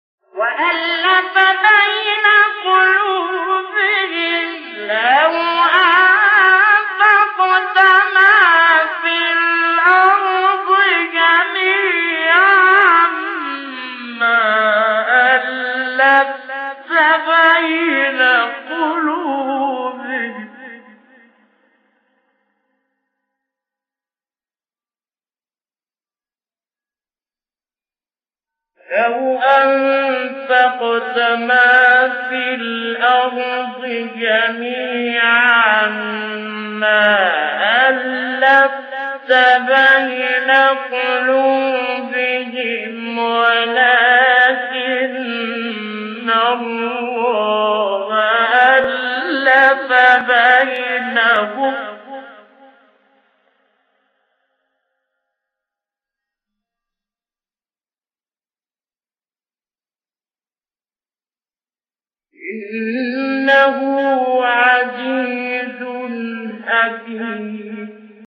مقطع تلاوت انفال شیخ علی محمود | نغمات قرآن
مقام: عجم * صبا